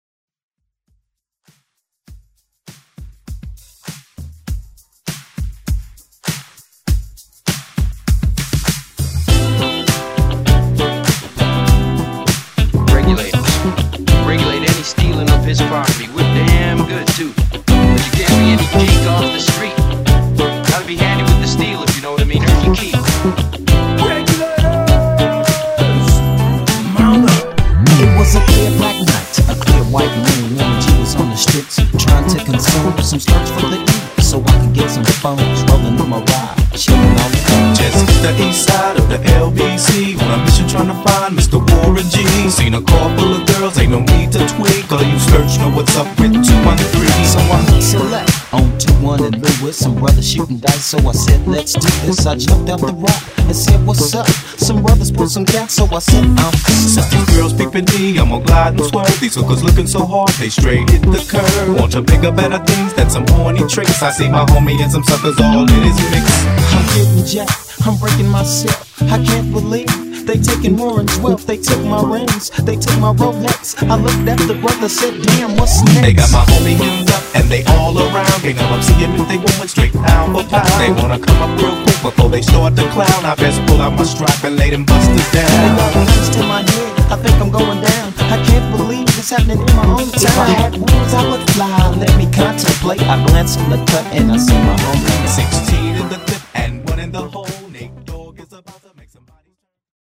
Genre: FUTURE HOUSE
Clean BPM: 126 Time